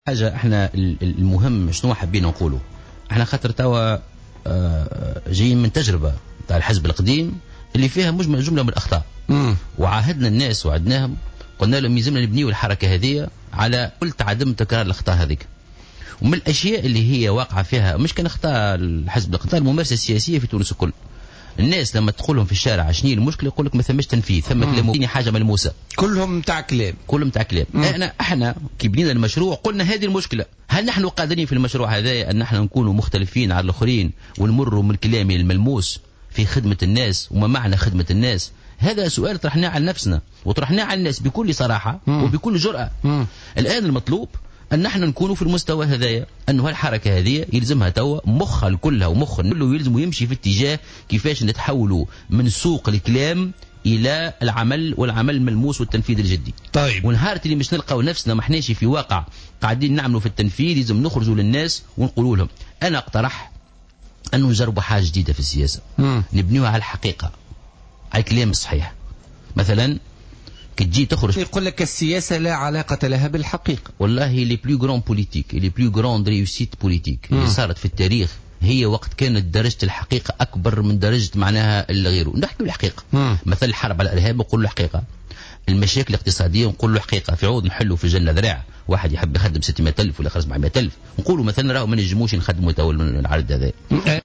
وأوضح مرزوق ضيف برنامج "بوليتيكا" اليوم أن تجربة الحزب القديم فيها جملة من الأخطاء وأنه يجب عدم تكرارها، مشيرا إلى أن حزبه الجديد سيكون مختلفا وسيعمل من أجل حل المشاكل العالقة و تقديم حلول ملموسة وفق برنامج قابل للتنفيذ بشكل جدي بعيدا عن مجرّد الكلام.